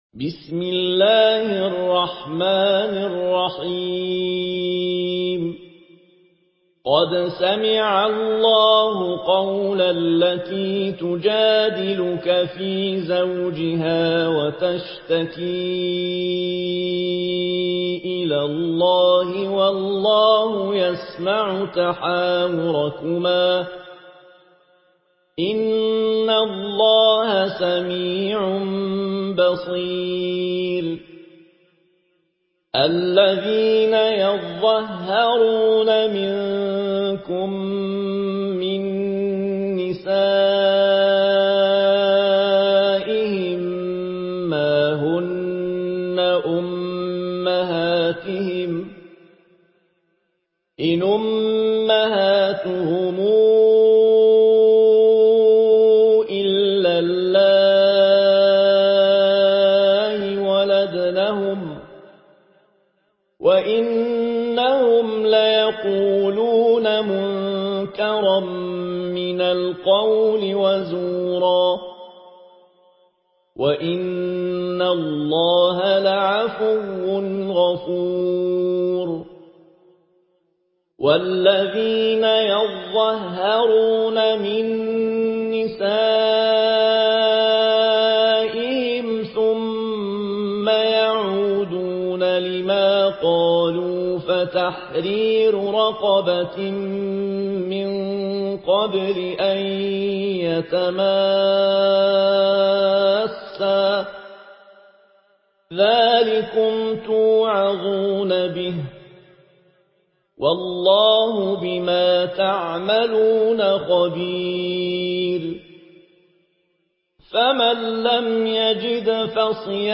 Murattal Warsh An Nafi